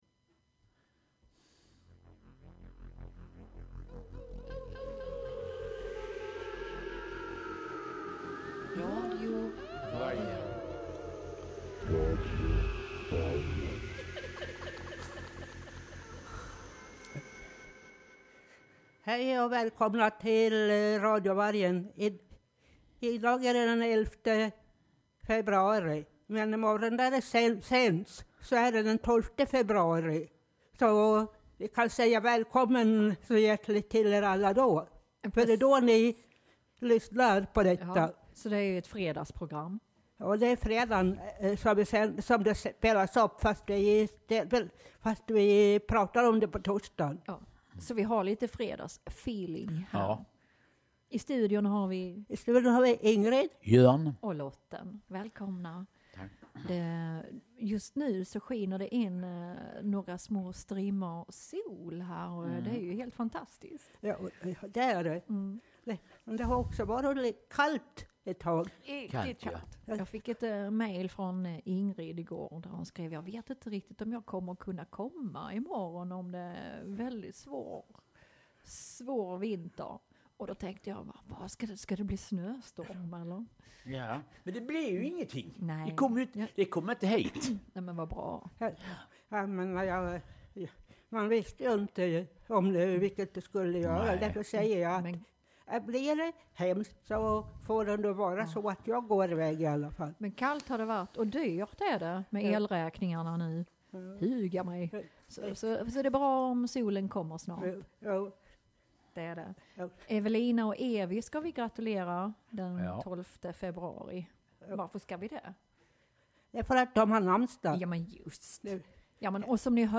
På söndag är det Alla Hjärtans Dag, därför spelar vi i dag enbart önskelåtar som har med KÄRLEK att göra. <3 Vi uppmärksammar också tre små rymdfarkoster (utan människor i) som ska undersöka om det har funnits liv på planeten Mars samt en kommande ny svensk TV-serie som skall handla om vår svenske kung, Carl Gustav.